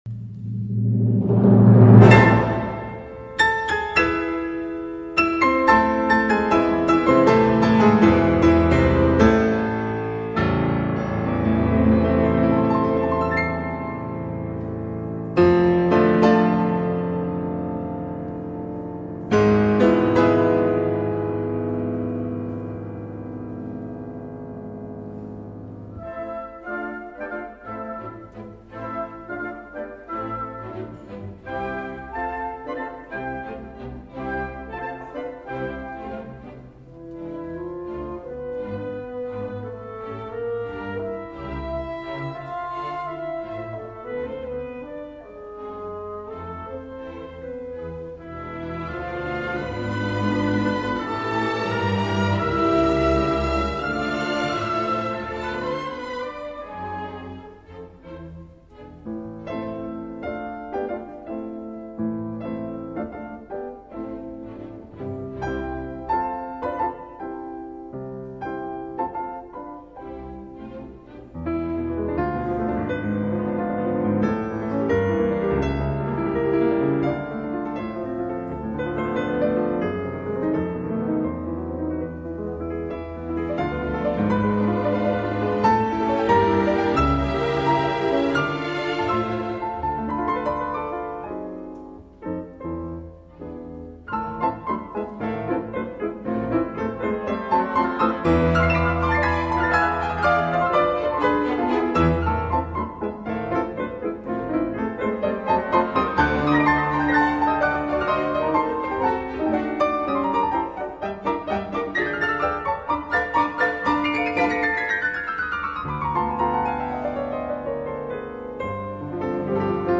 GRIEG, concerto pour piano n1, 01 maestoso.mp3